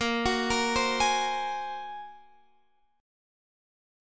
Bb7sus2#5 Chord (page 2)
Listen to Bb7sus2#5 strummed